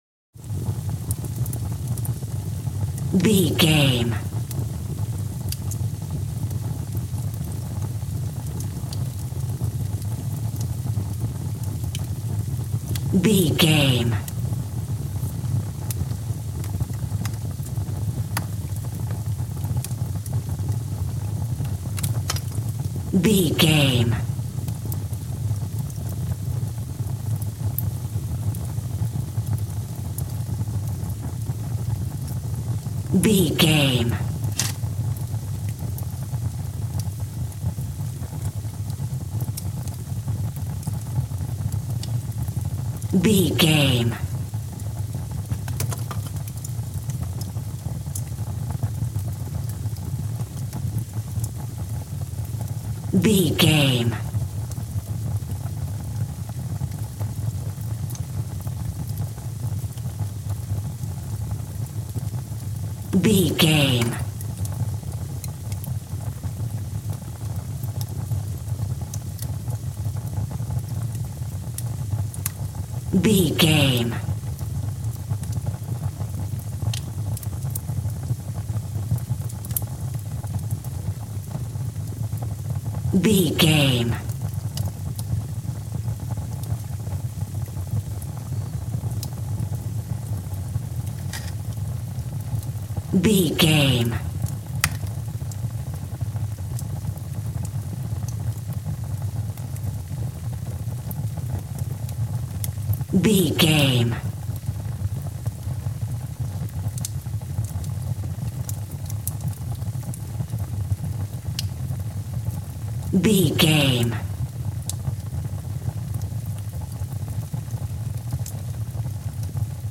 Fire with crakle
Sound Effects
torch
fireplace